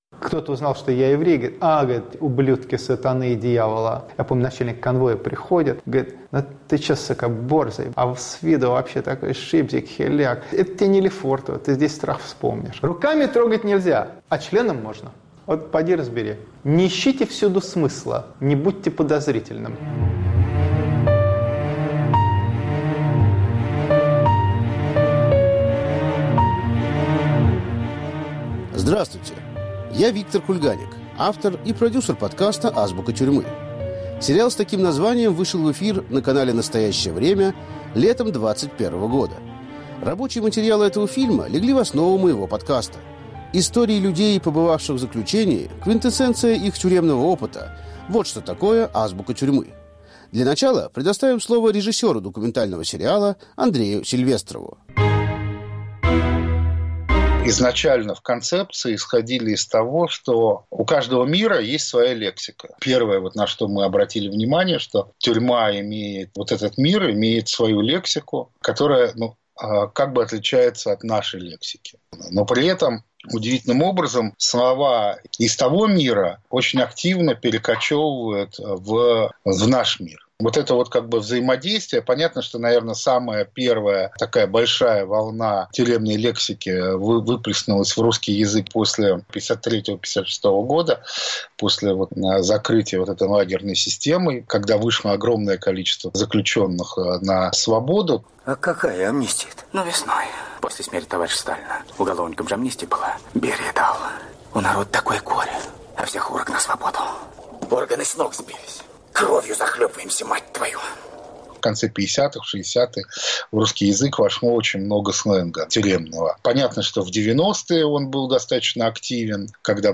Повтор эфира от 15 мая 2022 года.